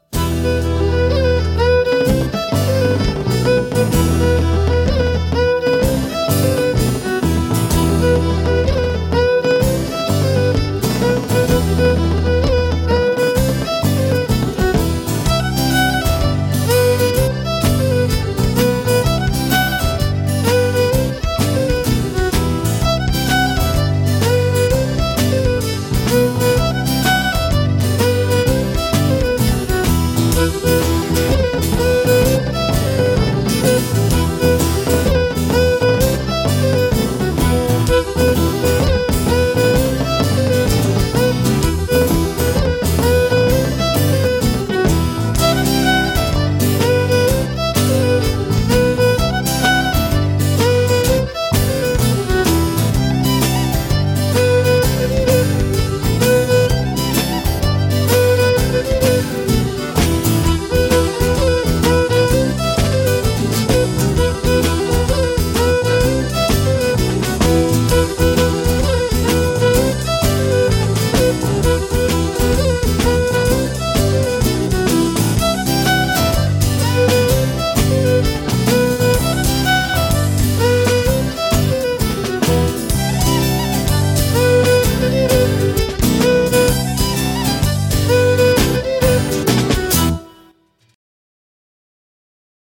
Hobsons Choice perform barn dances and ceilidhs throughout the year but most of our gigs are private parties and weddings.
Jig
Jig-Tune.mp3